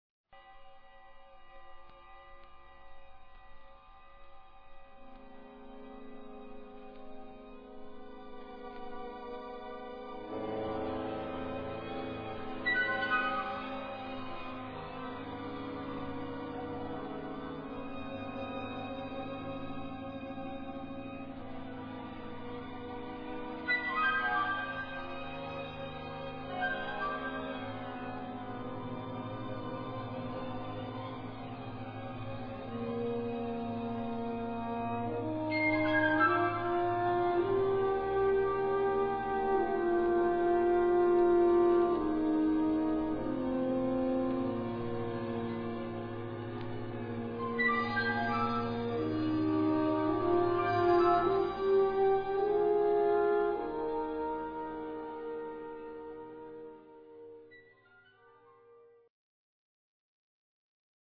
orchestra
(meno mosso)